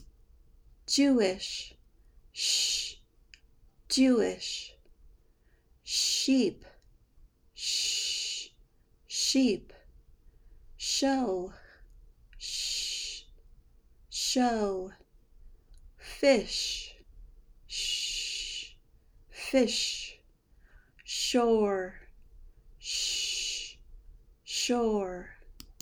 Pronounce SH and ZH in American English
Practice these words with SH
sh-words.mp3